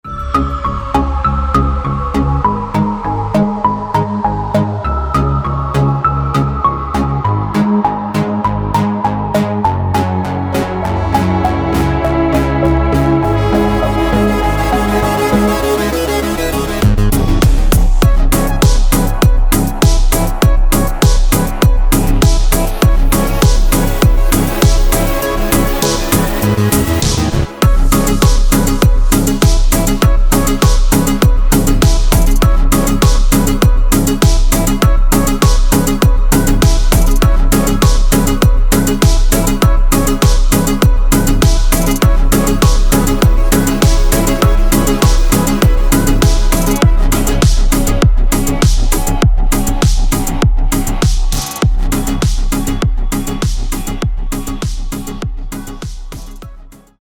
• Качество: 320, Stereo
Electronic
спокойные
house